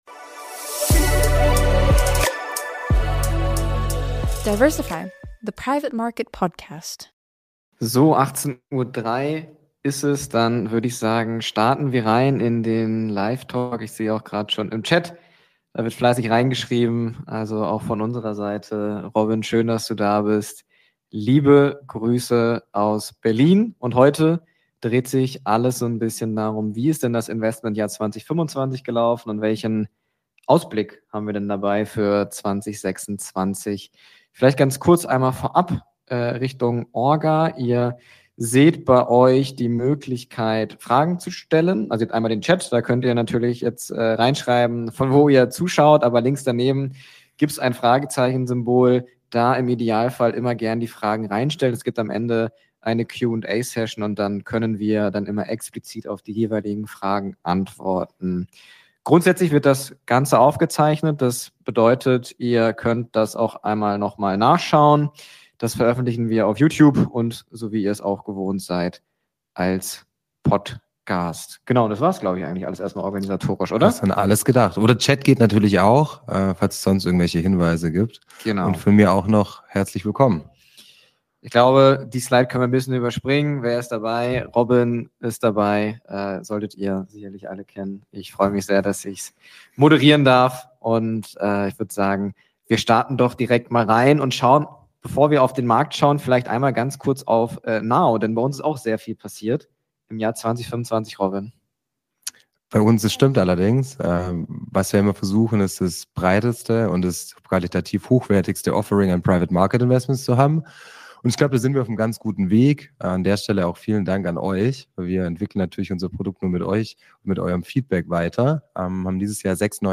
Live-Talk